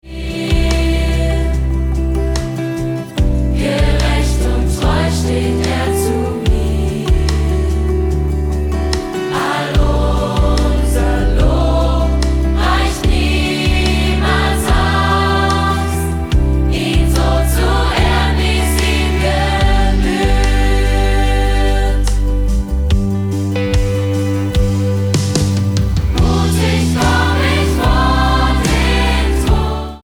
100 Sänger und Live-Band